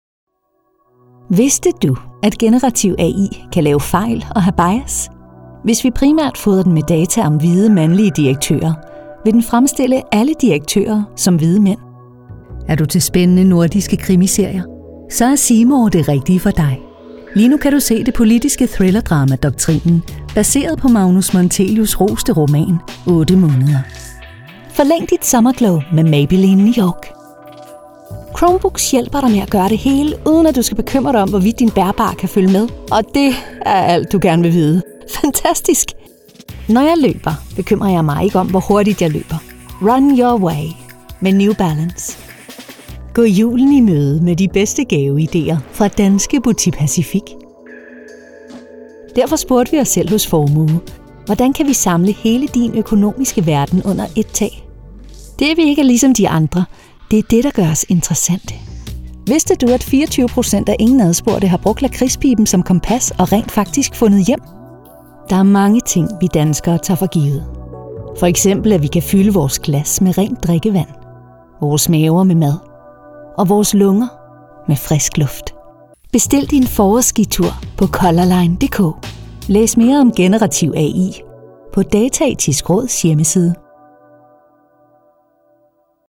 Imagens de rádio
Os melhores talentos profissionais de locução e atriz em dinamarquês e inglês, proporcionando a mais alta qualidade de som gravada em um estúdio profissional.